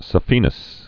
sa·phe·nous vein
(sə-fēnəs)